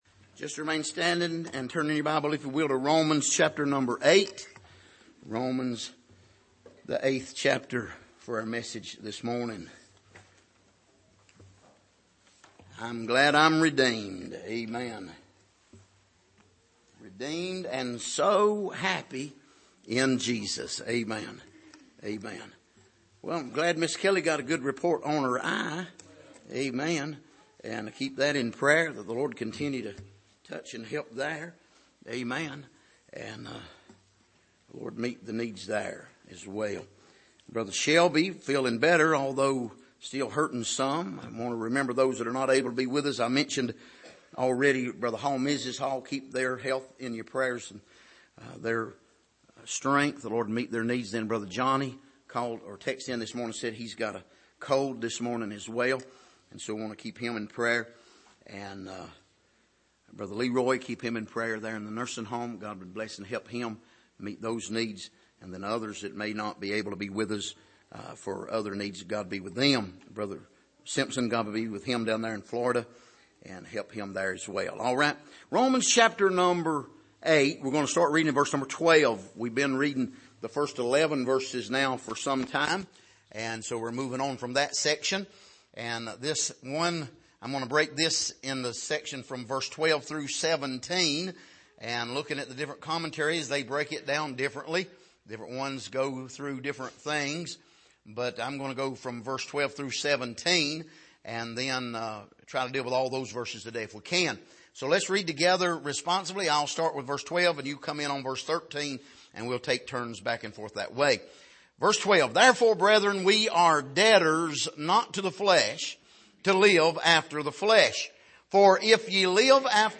Passage: Romans 8:12-17 Service: Sunday Morning